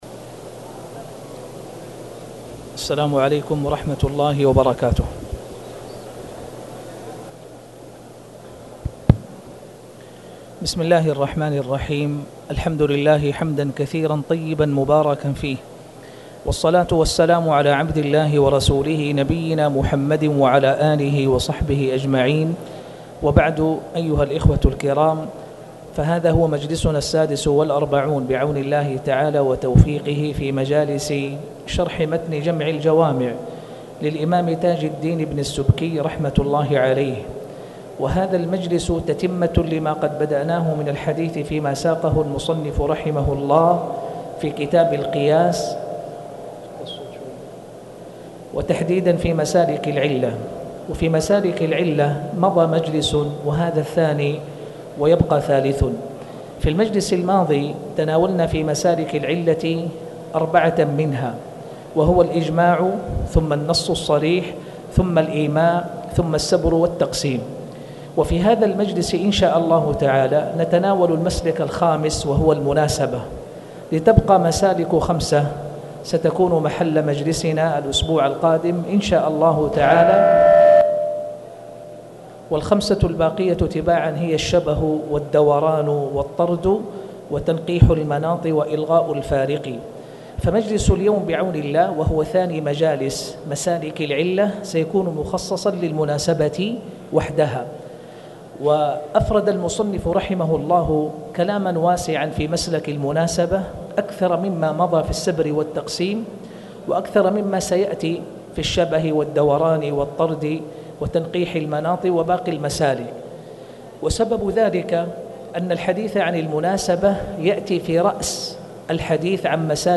تاريخ النشر ٢٩ ربيع الأول ١٤٣٨ هـ المكان: المسجد الحرام الشيخ